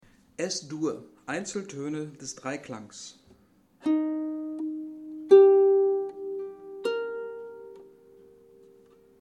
Hörbeispiel Einzeltöne